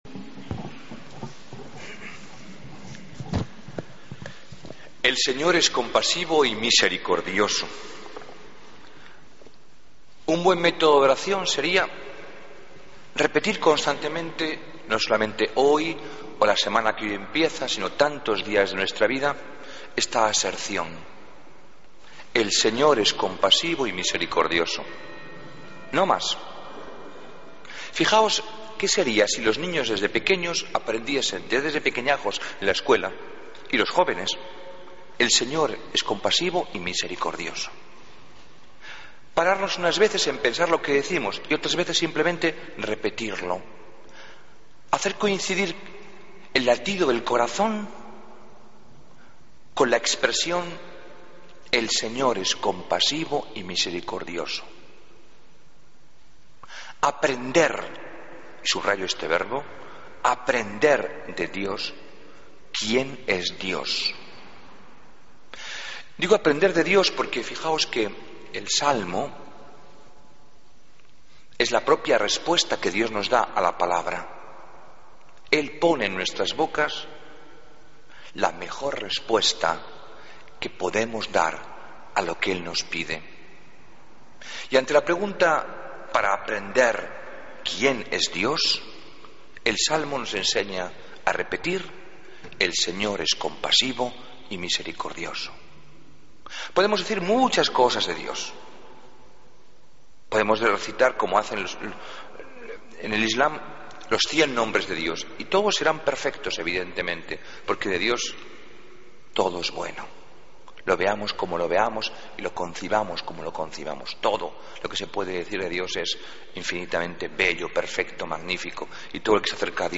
Homilía del Domingo 23 de Febrero de 2014